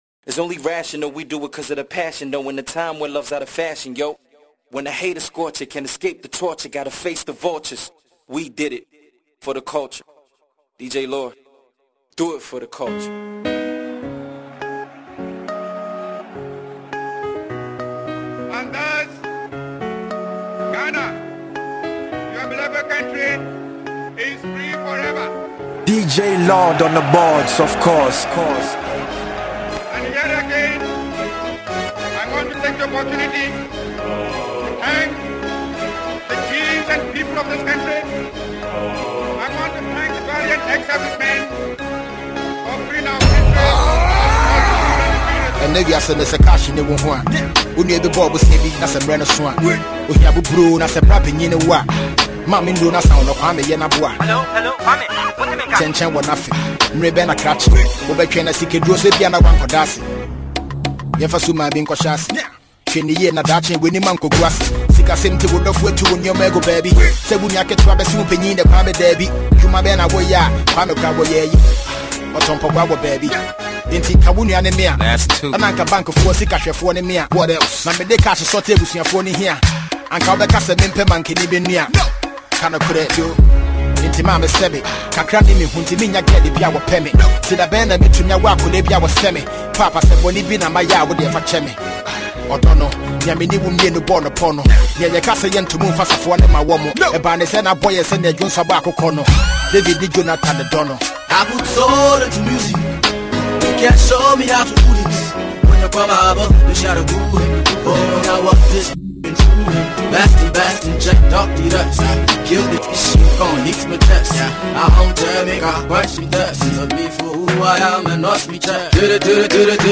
Ghanaian Hip-Hop mixtape
Genre: Mixtape